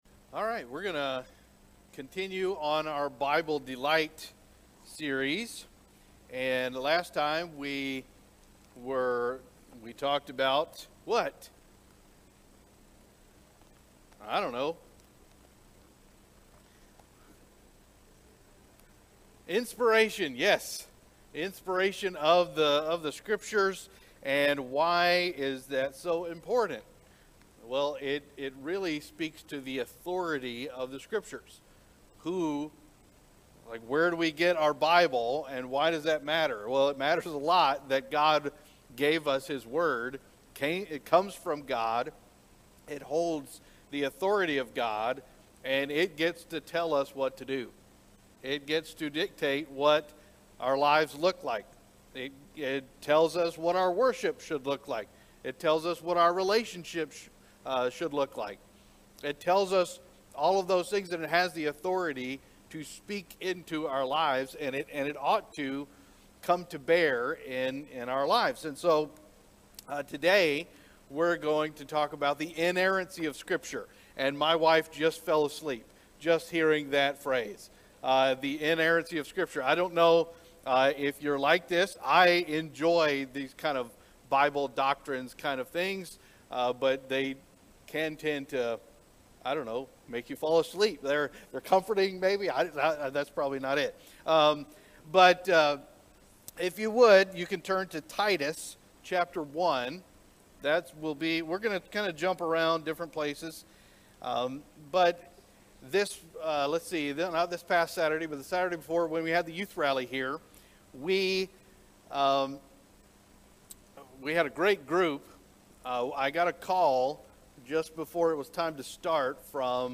Our latest Sunday morning worship service Messages from God’s word
Psalm 19:7-9 Share this sermon: